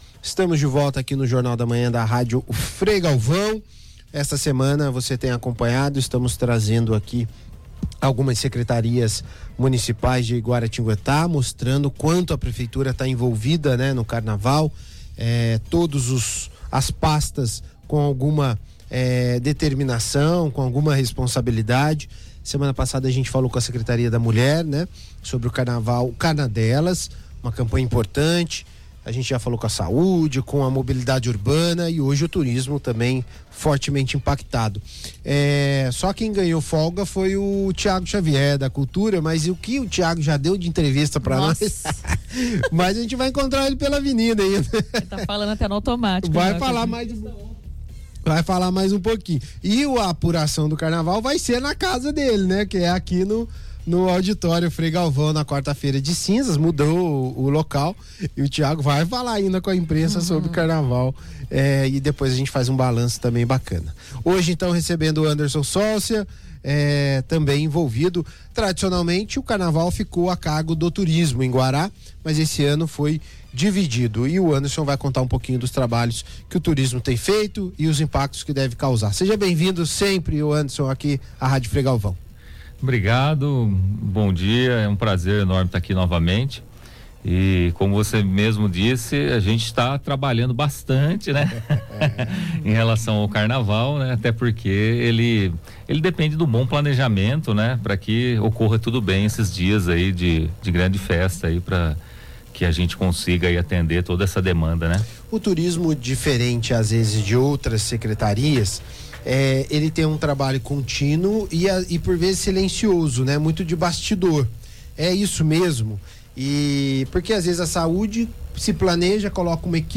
Em entrevista à Rádio Frei Galvão, o Secretário de Turismo, Anderson Solcia, destacou que o planejamento da festa ocorre integradamente entre diversas pastas, como Cultura, Saúde e Mobilidade Urbana, visando atender à alta demanda de turistas e moradores.